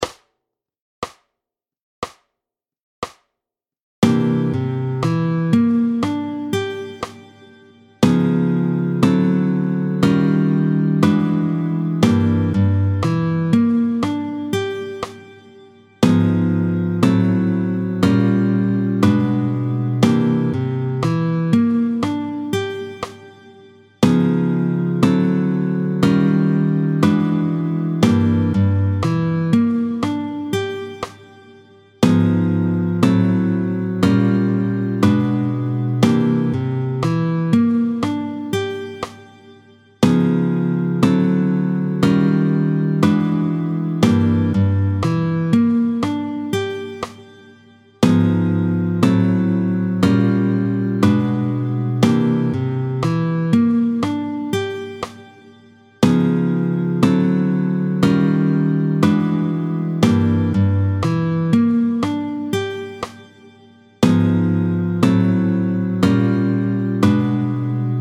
29-03 Les petits barrés de l’annulaire tempo 60